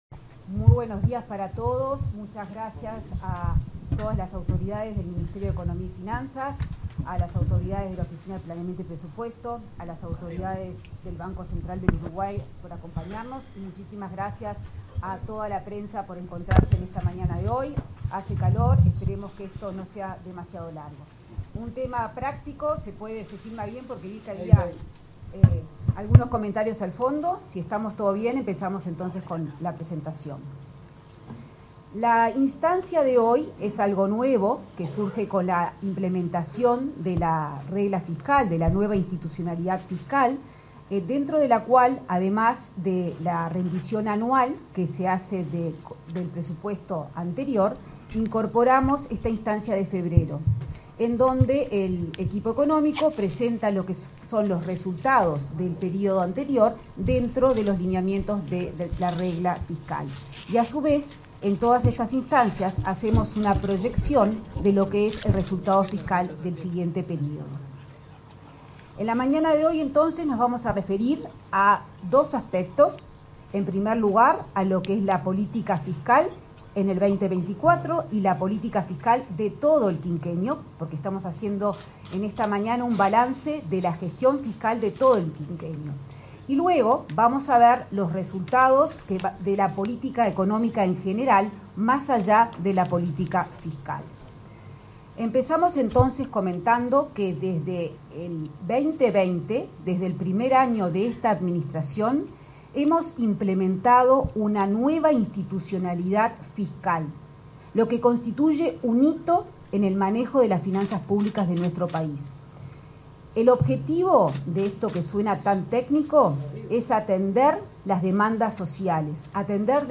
Palabras de la ministra de Economía y Finanzas, Azucena Arbeleche